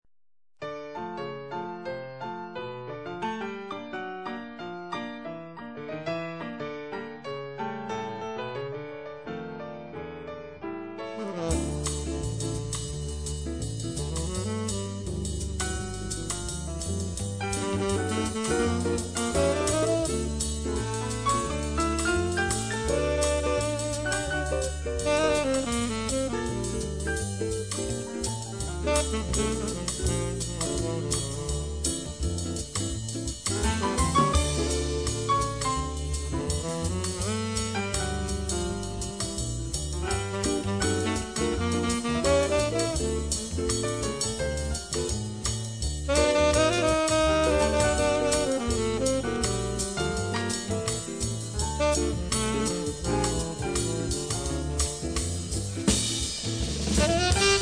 La première en salsa et la seconde en solo au sax.
servies par un son d'une extrême douceur.
Sax ténor